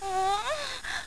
moan.wav